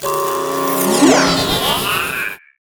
resteleport.wav